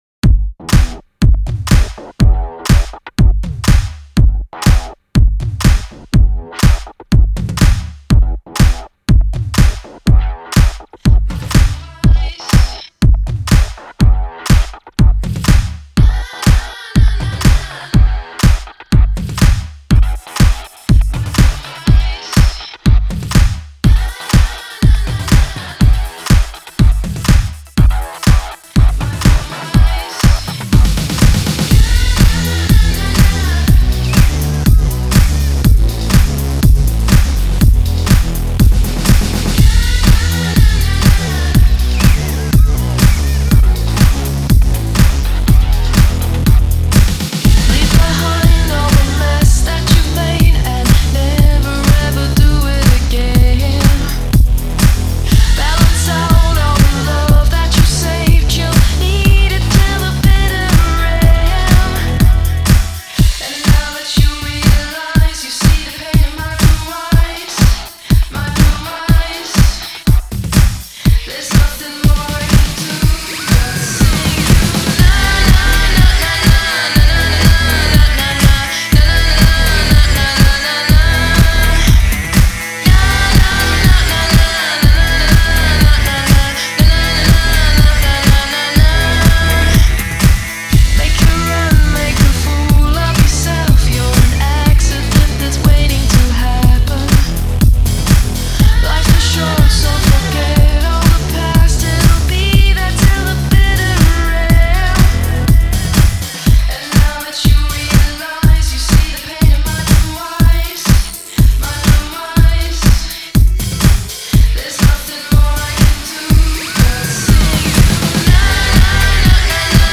Big room remix